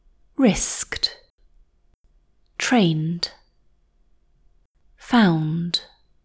Practice pronouncing words that end in consonants, focusing on the endings in particular before saying entire words, and hold back the instinct to add a vowel sound like uh by clapping the syllables — the beats in the word.